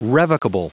Prononciation du mot revocable en anglais (fichier audio)
Prononciation du mot : revocable